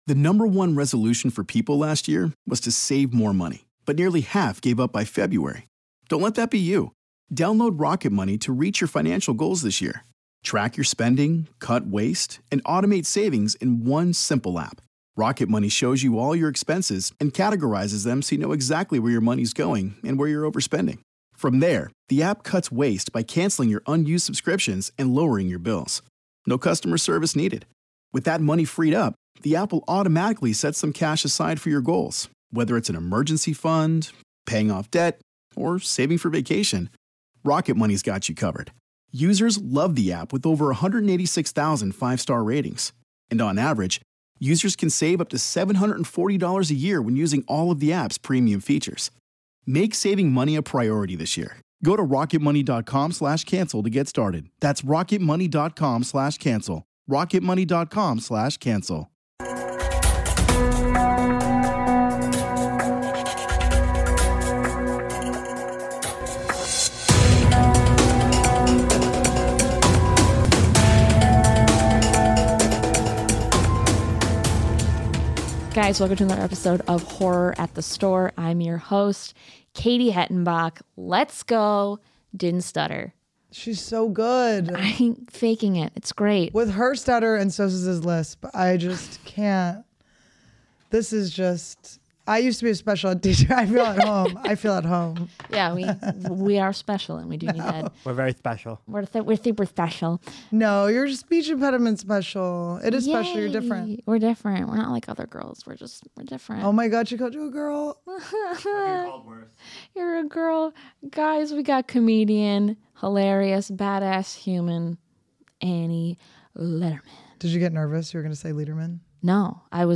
Comedian and horror fan Annie Lederman in to talk Terrifier and a lot of other random things on this weeks episode!